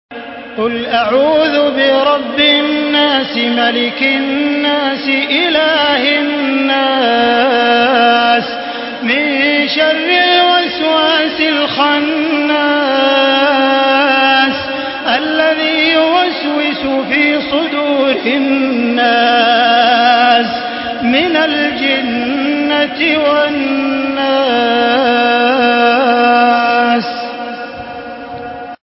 Surah Nas MP3 by Makkah Taraweeh 1435 in Hafs An Asim narration.
Murattal Hafs An Asim